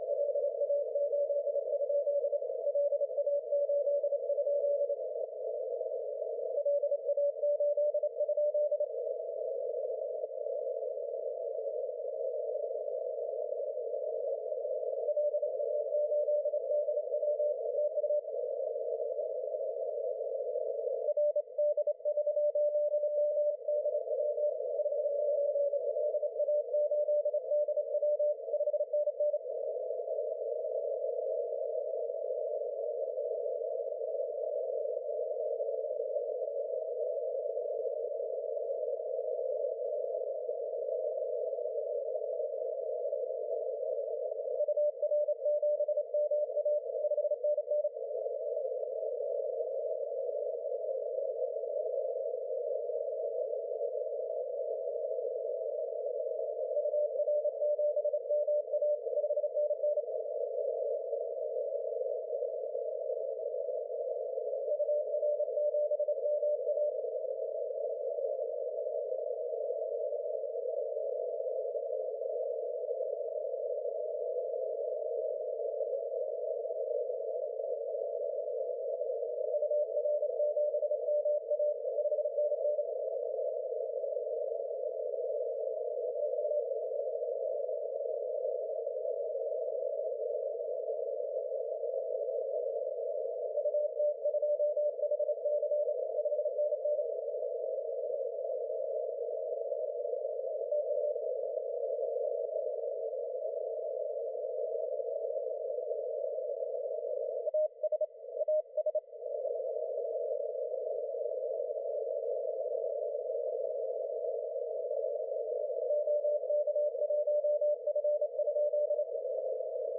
Date: March 22, 2021 Time: 07:25 GMT Freq: 14020 KHz Mode: CW QTH: Bol’shoy Begichev Island.